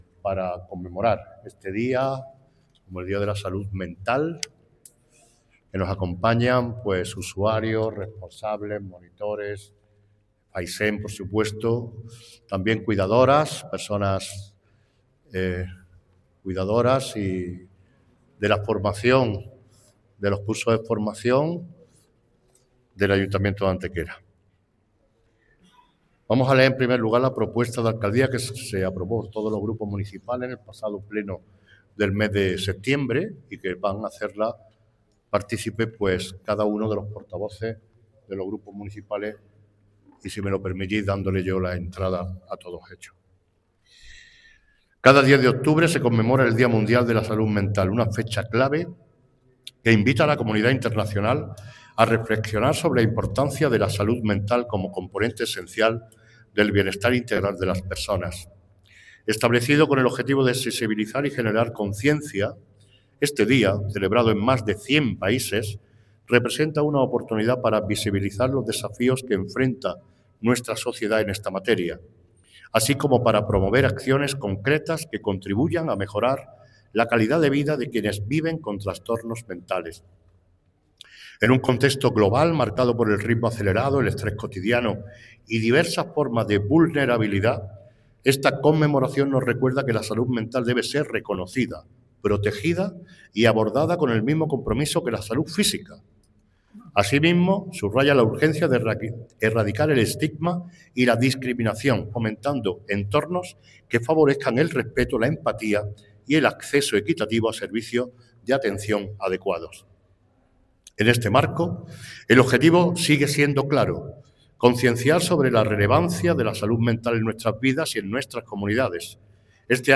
El alcalde de Antequera, Manolo Barón, ha presidido en la mañana de hoy viernes 10 de octubre un acto conmemorativo del Día Mundial de la Salud Mental, celebrado en el patio del Ayuntamiento con la participación de los portavoces de los tres grupos políticos con representación municipal, así como de usuarios, técnicos y familiares del Centro Social FAISEM de Antequera.
Cortes de voz